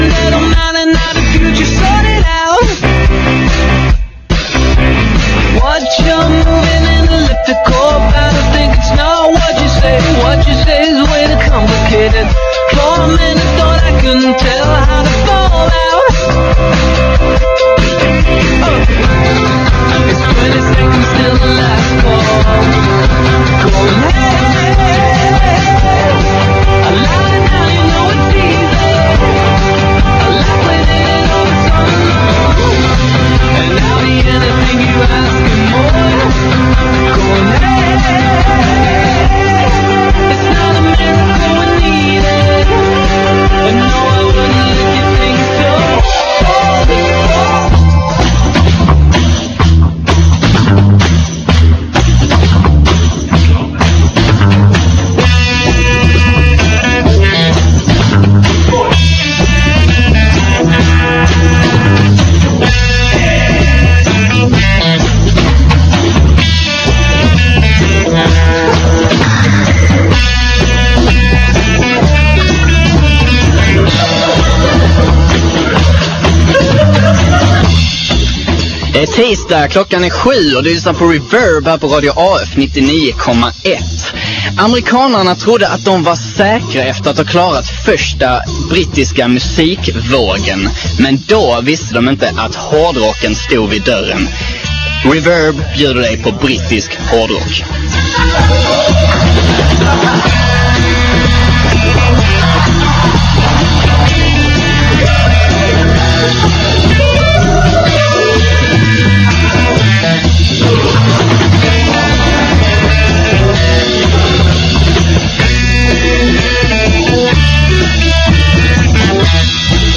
Följ med Reverb på en rundtur bland några av de första och största hårdrockslegenderna från 70-talet.